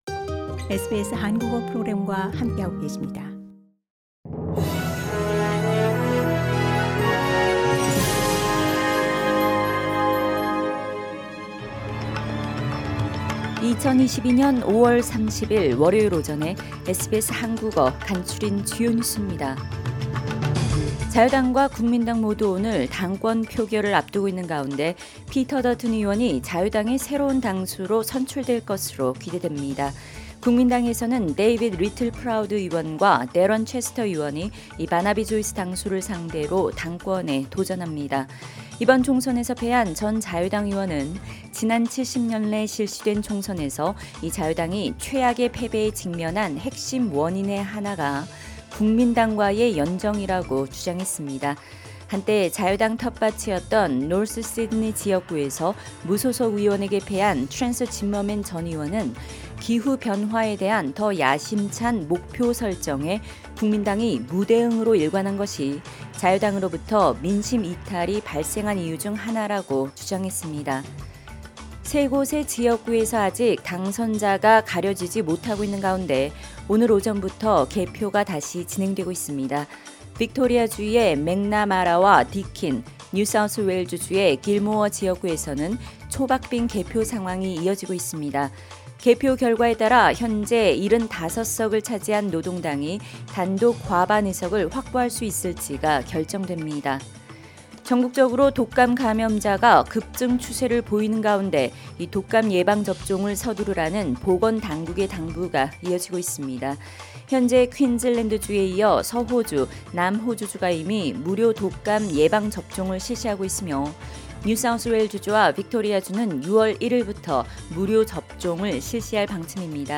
SBS 한국어 아침 뉴스: 2022년 5월 30일 월요일
2022년 5월 30일 월요일 아침 SBS 한국어 간추린 주요 뉴스입니다.